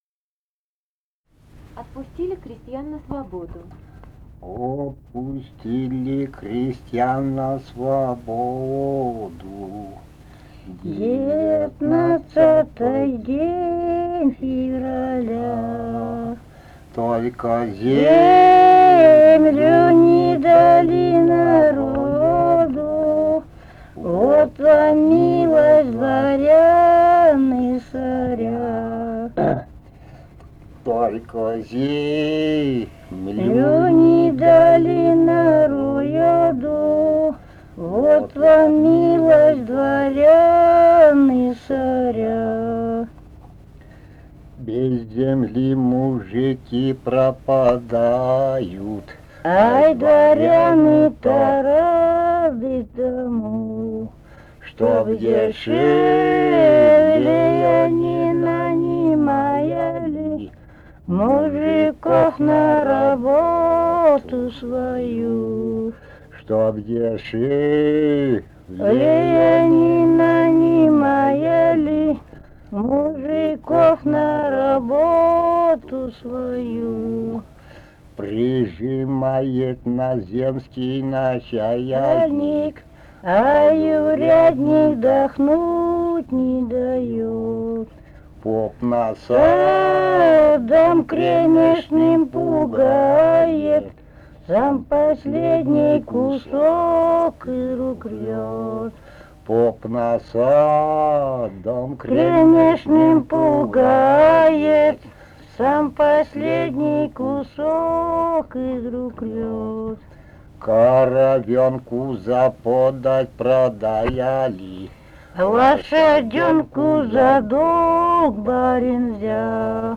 полевые материалы
Алтайский край, д. Пономарёво Чарышского района, 1967 г. И1020-12